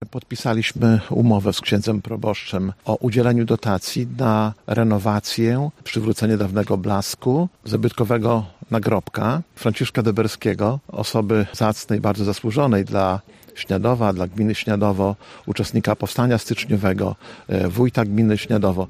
Dążymy do tego, aby zasłużeni obywatele naszego regionu zostali odpowiednio uhonorowani-podkreśla starosta Łomżyński, Lech Szabłowski: